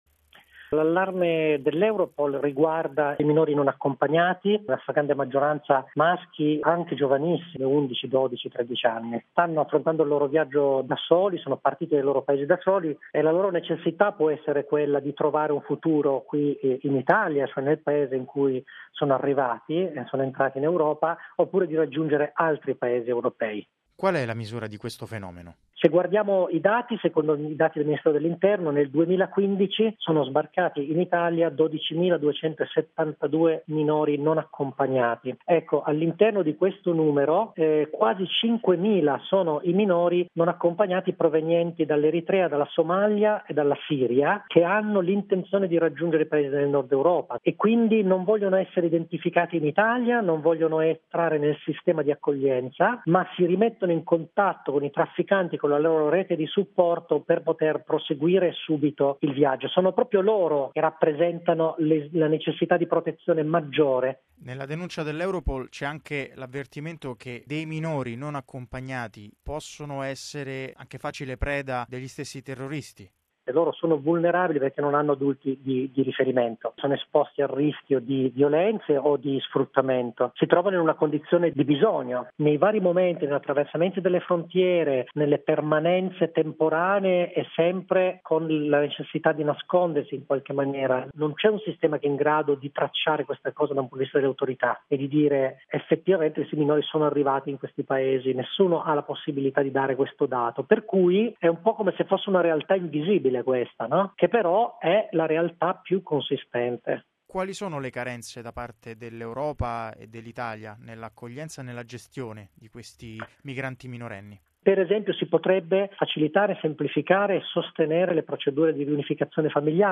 ha raggiunto al telefono